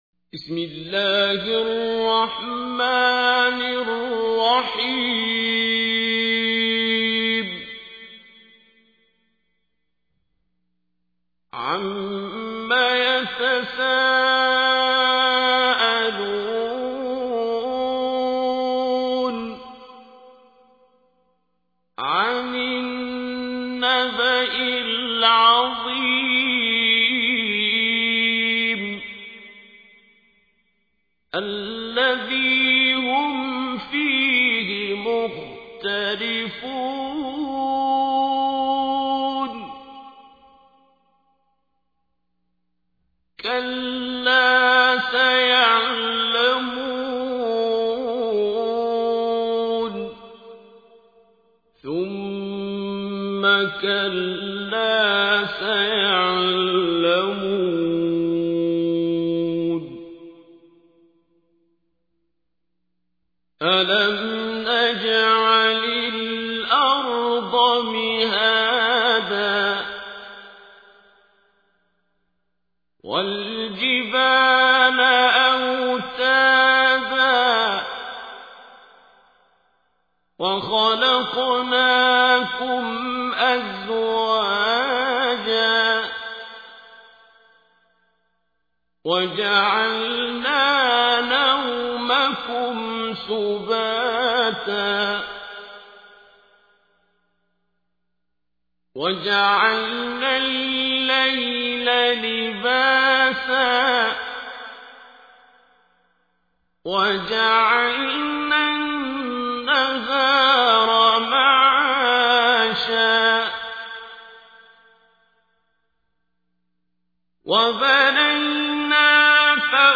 تحميل : 78. سورة النبأ / القارئ عبد الباسط عبد الصمد / القرآن الكريم / موقع يا حسين